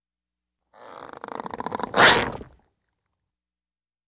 Rubber Stretches; Rubber Processed Stretching And Bending. - Cartoon, Stretchy Rubber